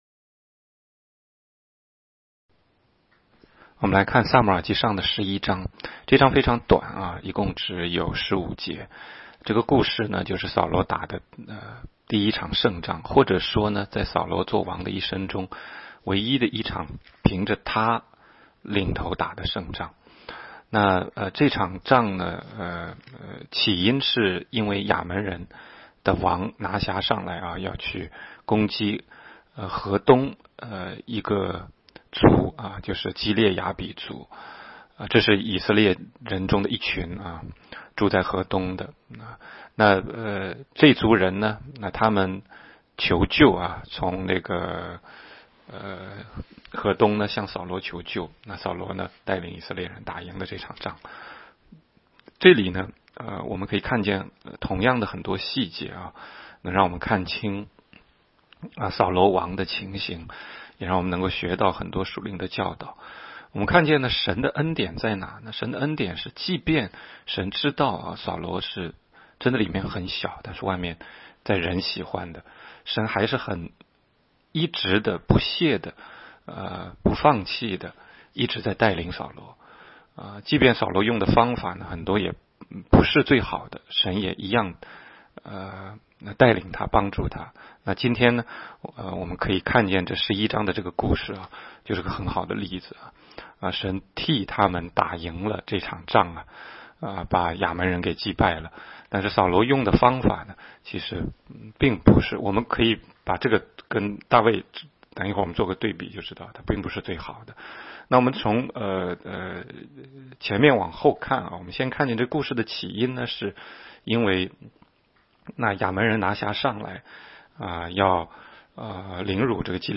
16街讲道录音 - 每日读经-《撒母耳记上》11章